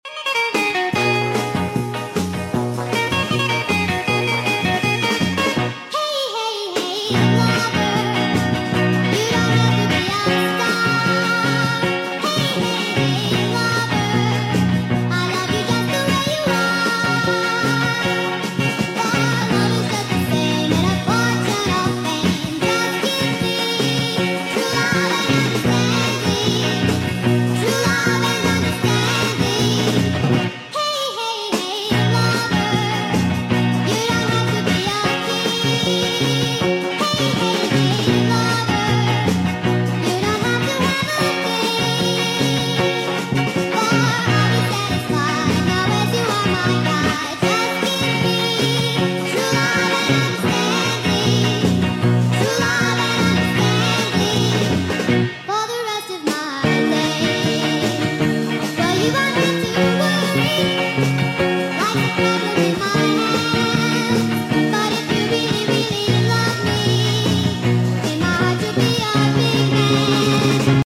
(Incluso si alguien de ustedes sabe coreano no creo que se entienda de lo que hablan porque recorté partes del audio y las puse al azar, originalmente es un audio de 2 minutos)